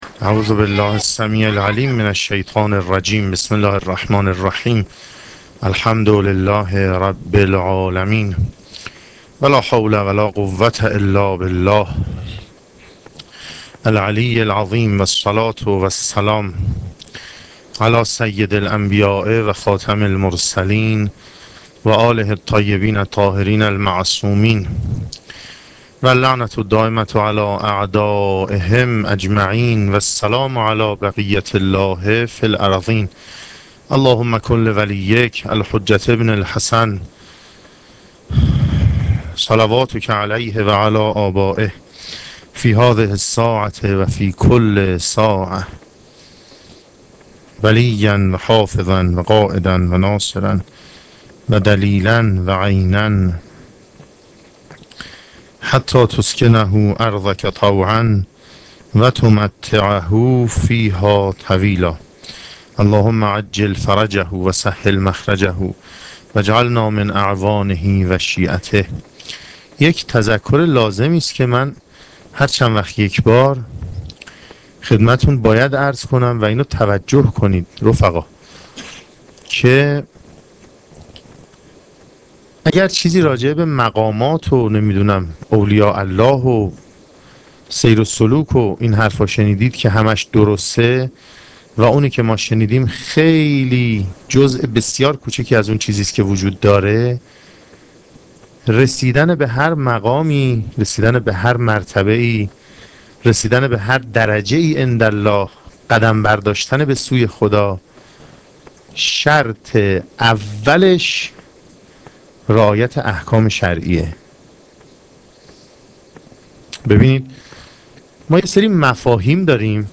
سخنرانی شب پنجم